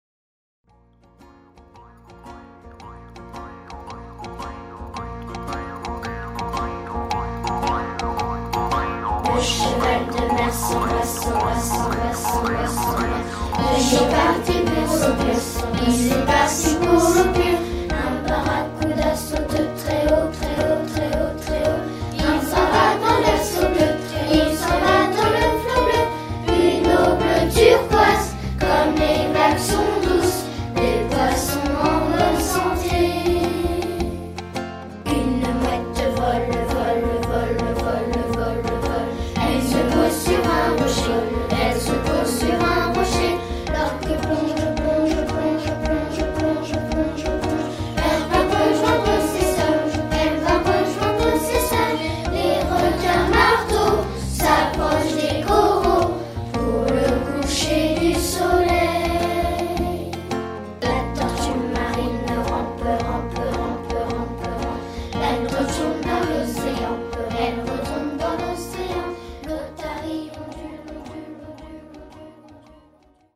Chantée à 2 voix